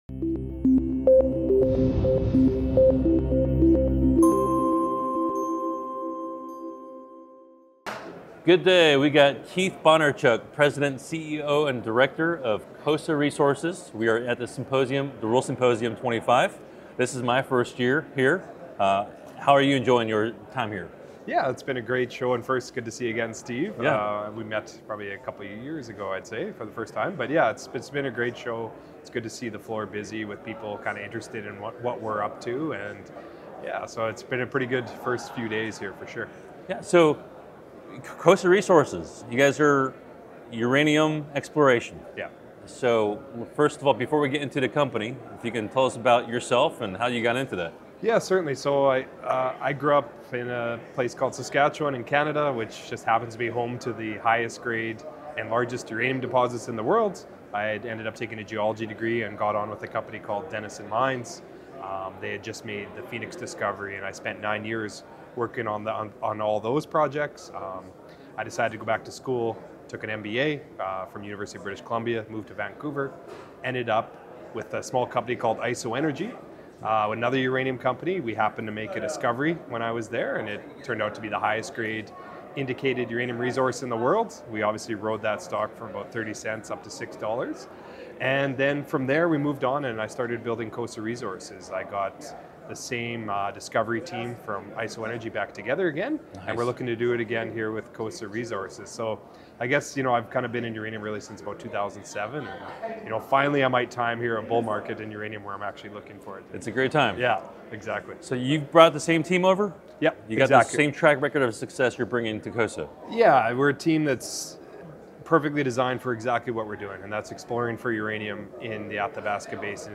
Exploring Uranium with COSA Resources: An In-depth Interview
recorded live at the Rule Symposium 25.